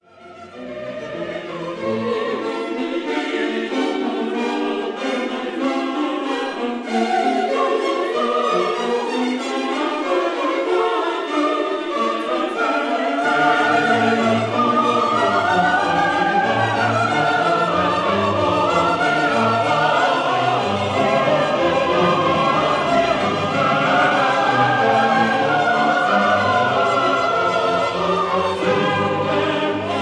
soprano
contralto
tenor
bass